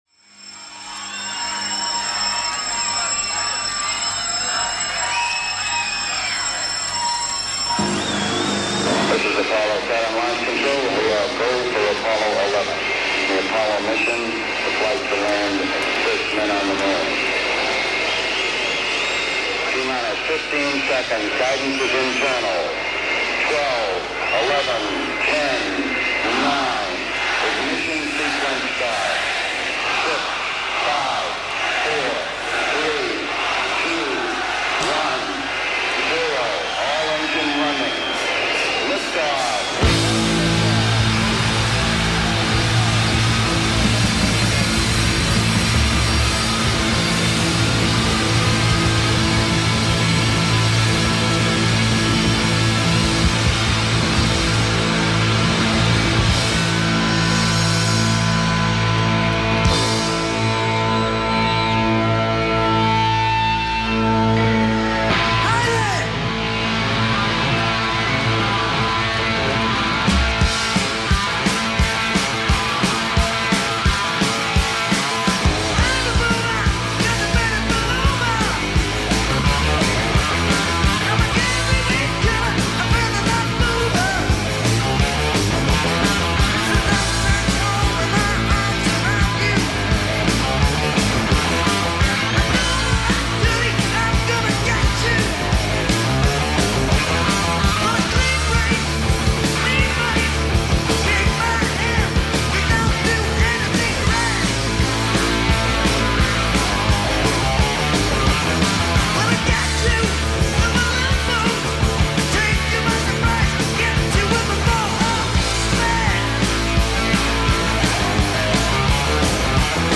as a hard rock band in Cardiff
Hard rock with the emphasis on Hard.